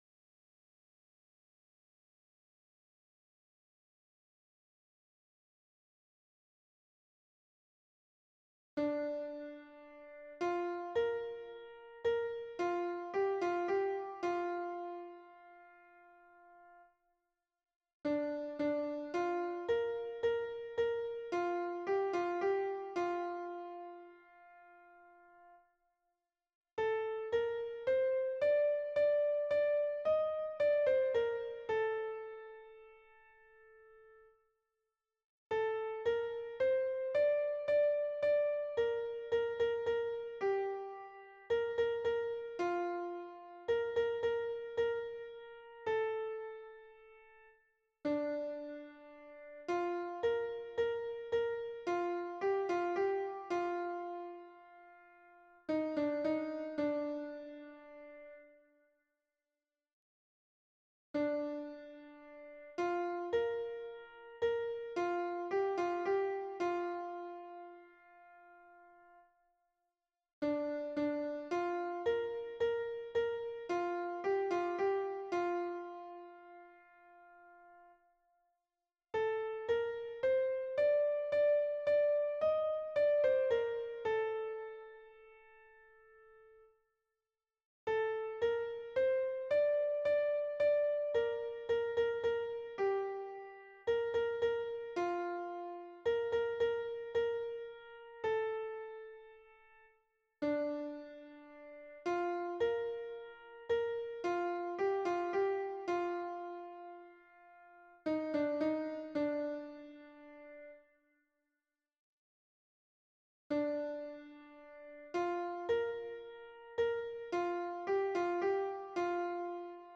à 3 voix mixtes
MP3 version piano
Soprano